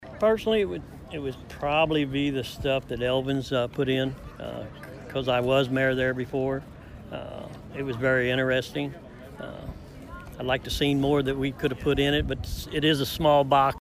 The Mayor of Park Hills, Danny Naucke, explains there were some items in the capsule that were especially important to him.